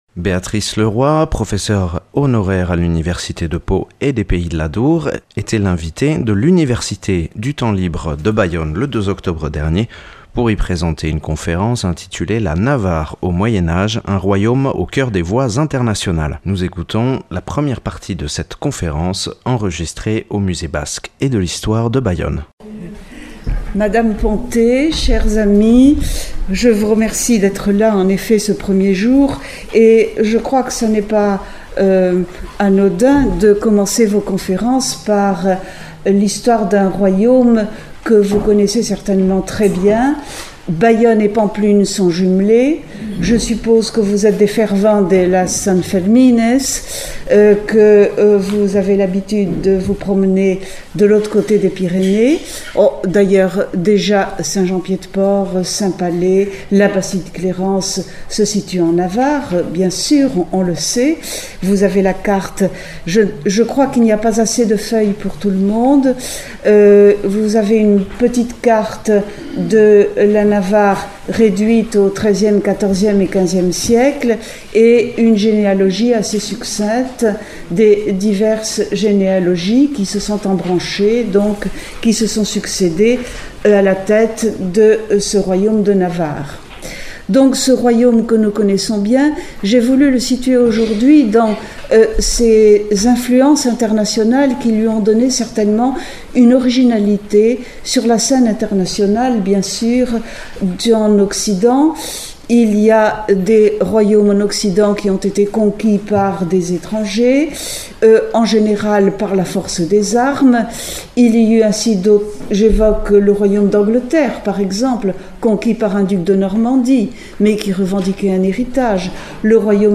(Enregistré le 02/10/2015 dans le cadre de l’université du temps libre de Bayonne)